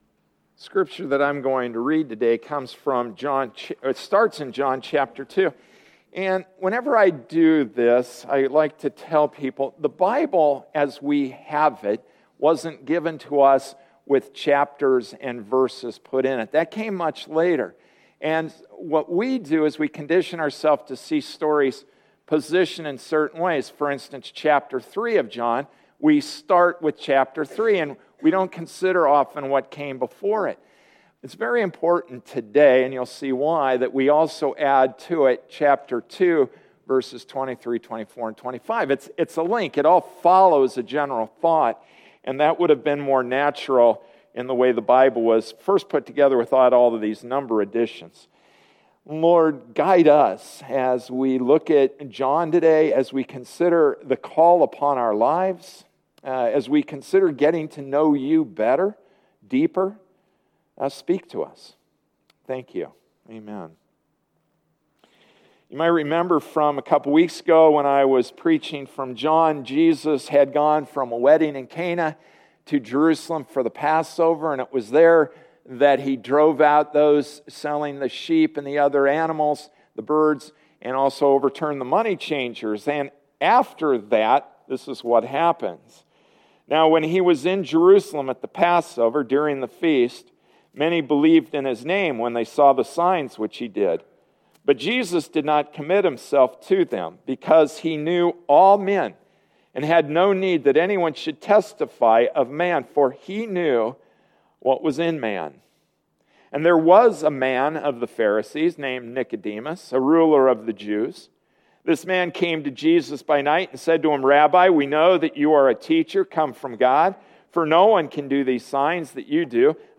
Passage: John 2:23 - 3:15 Service Type: Sunday Morning Service “How,” John 2:23-3:15 Introduction: (2010) Pew Research claims 2.18 billion Christians worldwide out of 6.9 billion people What do we know about Jesus?